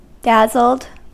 Ääntäminen
Synonyymit slack-jawed Ääntäminen US Haettu sana löytyi näillä lähdekielillä: englanti Dazzled on sanan dazzle partisiipin perfekti.